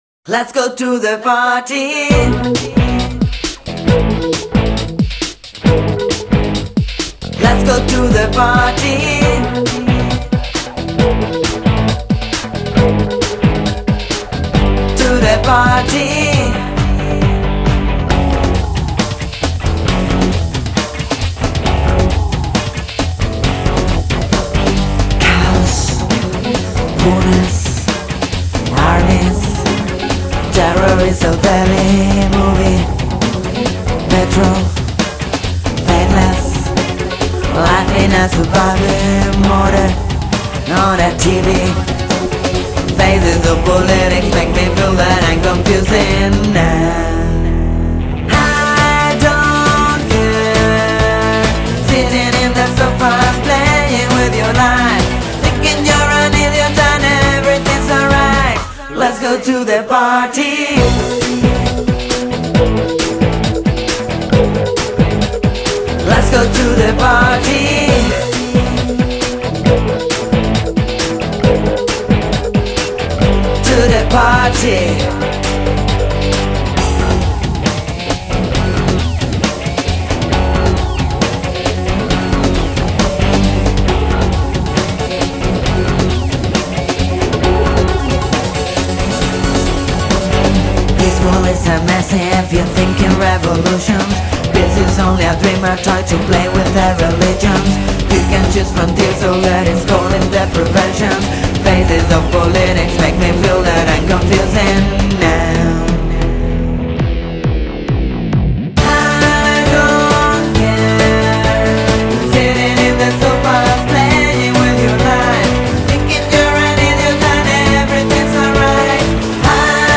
Rock, Electrónica y caña.
El Techno y House están también muy presentes.